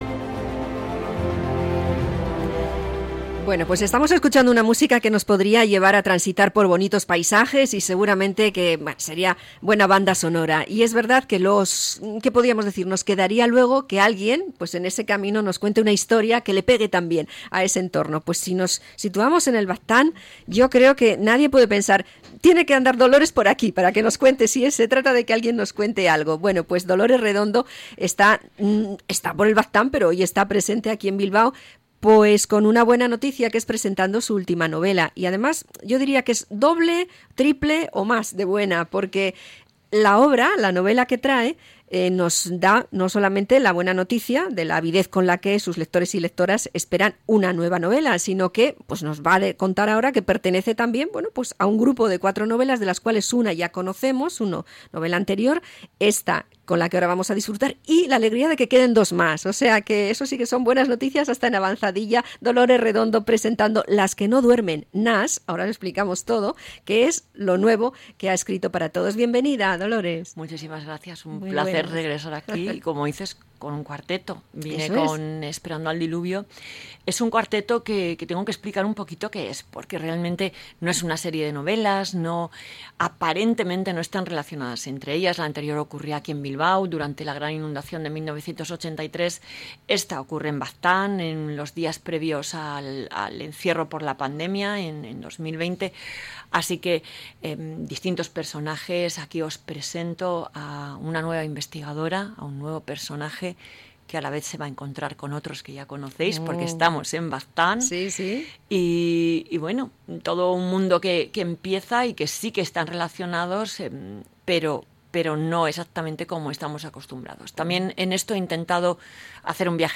Entrevista a la autora Dolores Redondo
La autora del fenómeno literario de los últimos años nos visita en estudios para presentar su último libro